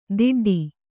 ♪ diḍḍi